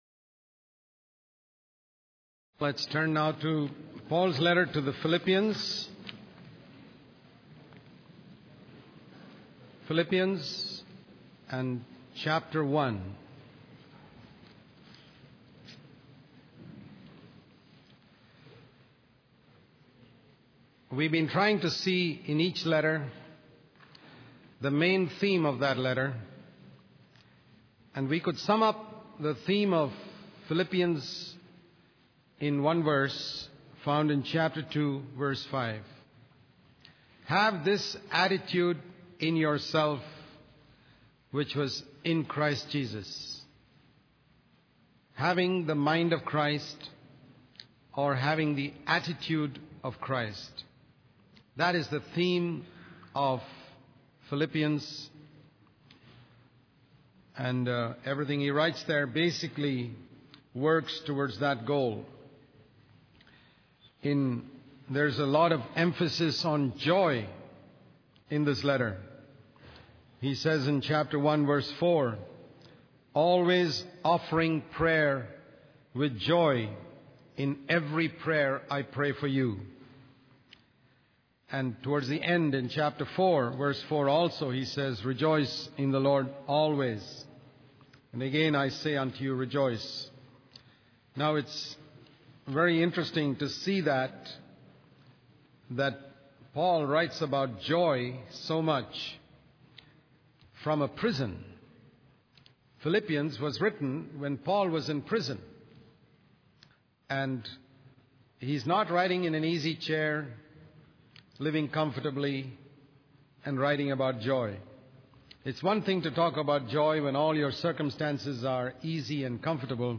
In this sermon, the speaker discusses how Caesar's family may have been converted to Christianity through the influence of Paul. He imagines that Paul, who was chained to a Roman soldier for eight hours, would have used that time to share the gospel with him. The speaker emphasizes the difference between serving for honor and having a servant's attitude, using an example of a minister sweeping the floor for a few seconds to gain reputation.